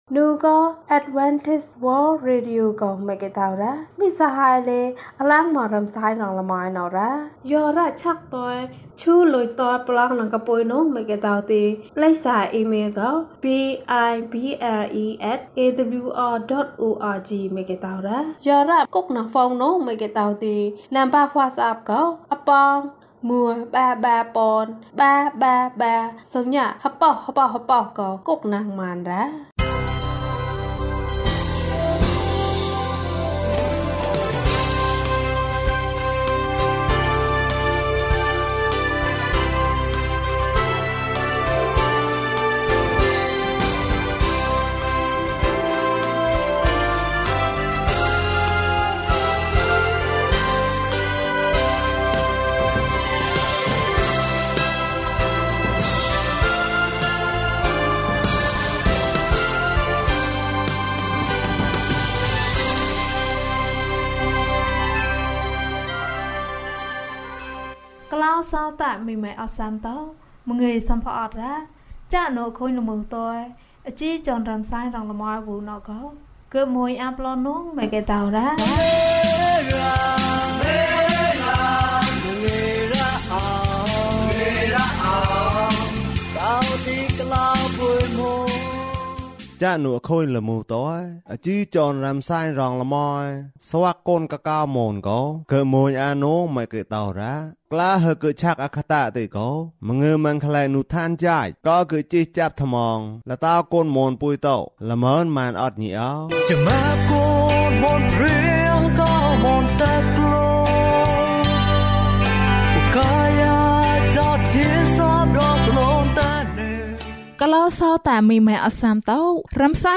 ခရစ်တော်ထံသို့ ခြေလှမ်း။၂၇ ကျန်းမာခြင်းအကြောင်းအရာ။ ဓမ္မသီချင်း။ တရားဒေသနာ။